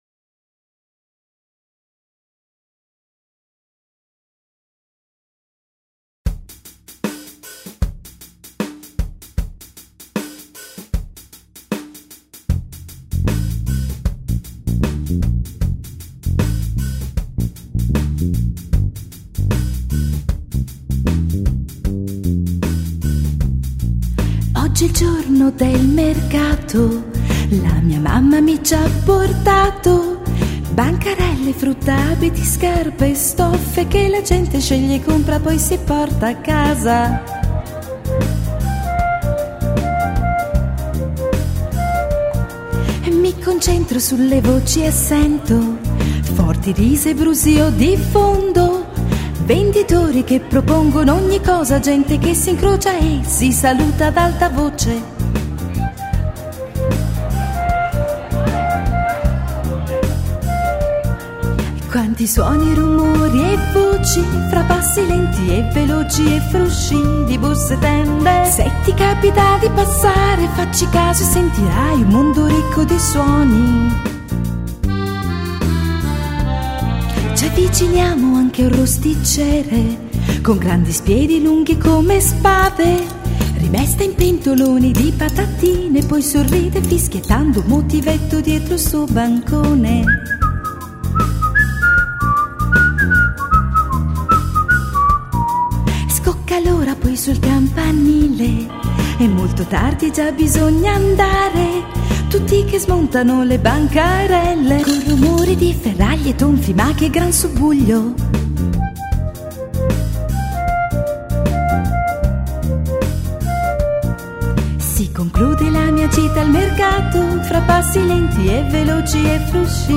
canzone